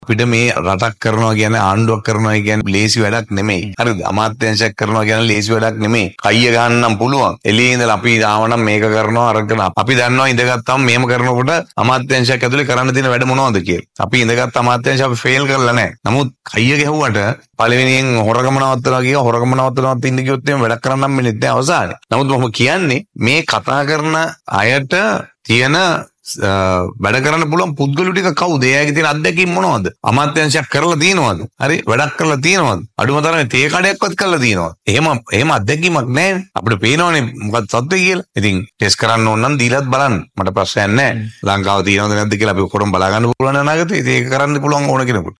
වී.එෆ්.එම් යු ටියුබ් නාලිකාවේ අද විශාකය වු වී එයිට් වැඩසටහනට එක්වෙමින් දයාසිරි ජයසේකර මහතා මේ බව ප්‍රකාශ කළා.